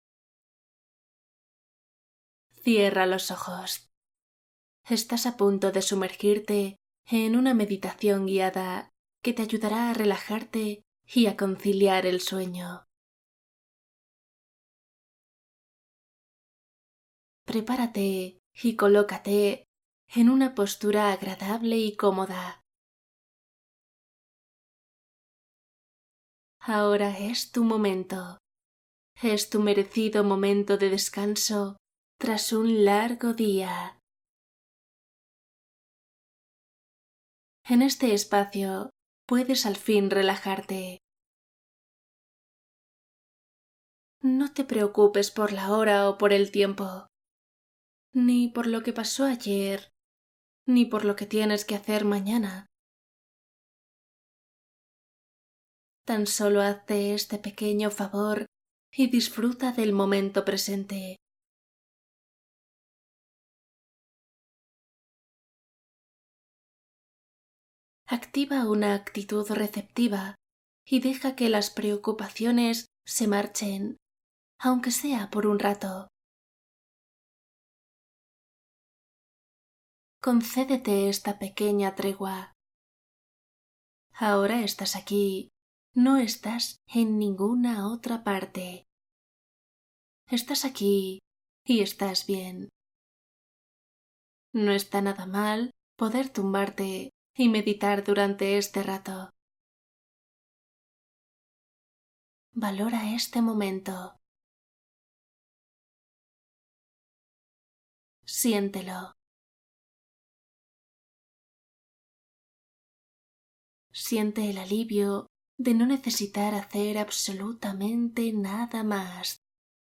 Relajación y sueño profundo: meditación para aliviar insomnio